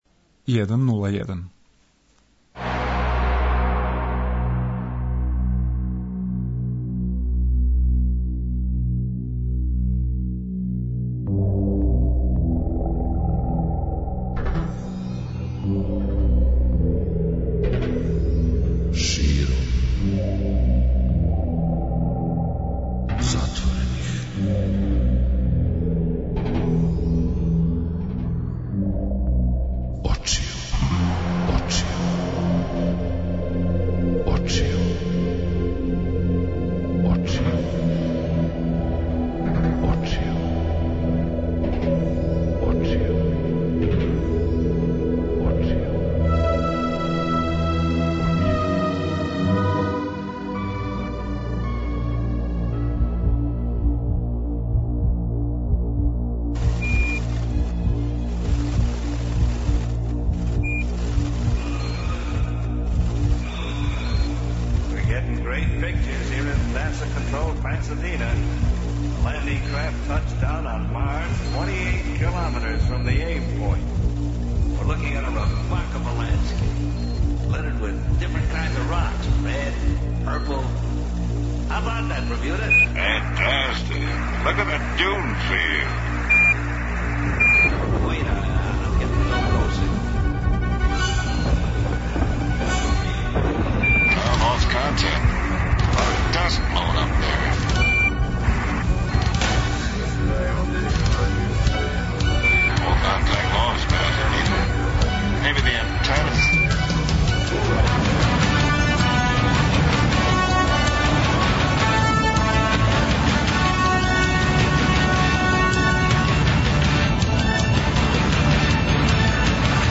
Из света "музике" овога пута слушамо како звучи планета Јупитер, али и специјалан инструмент коришћен за снимање хорор-филмова.